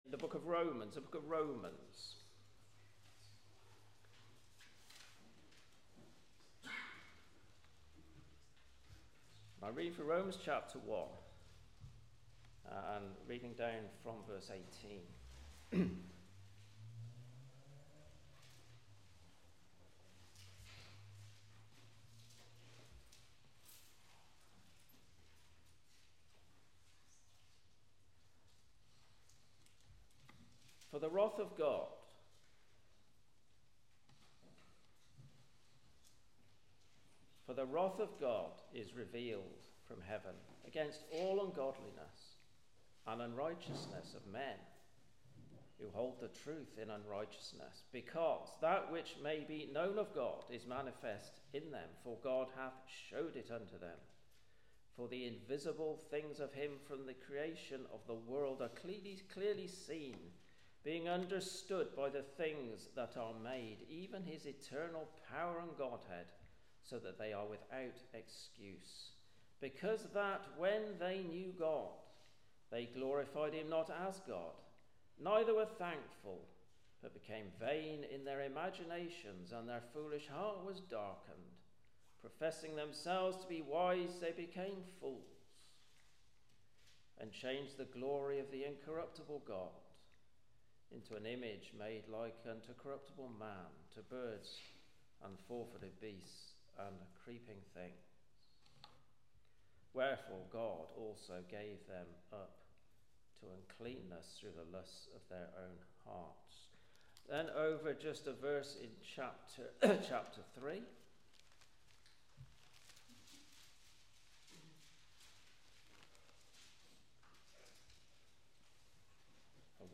Gospel Meetings